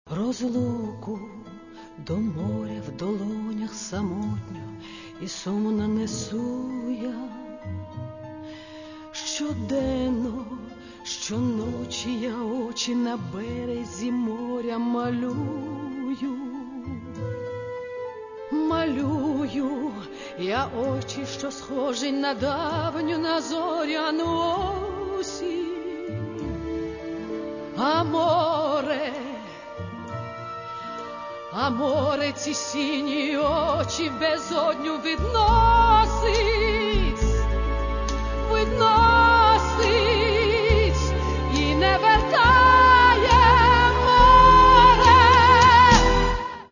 Каталог -> Естрада -> Поети та композитори